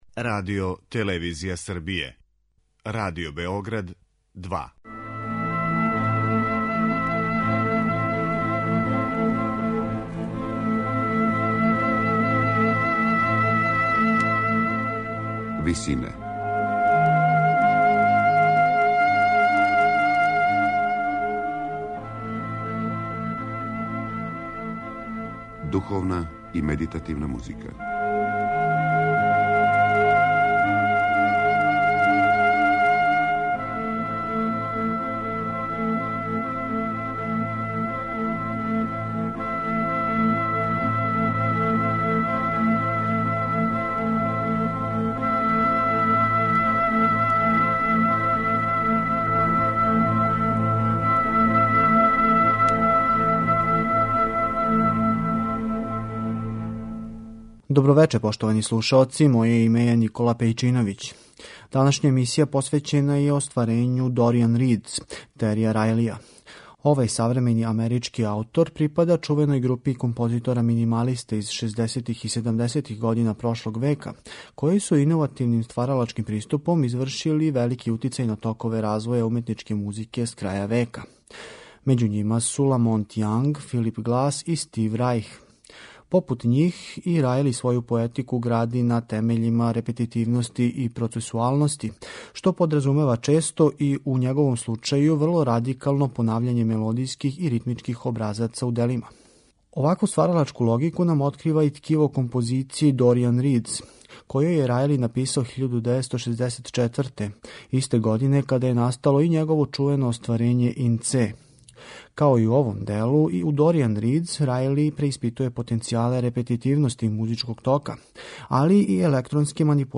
На крају програма, у ВИСИНАМА представљамо медитативне и духовне композиције аутора свих конфесија и епоха.
Амерички минималиста је ово остварење написао 1964. године, за соло извођача и два магнетофона.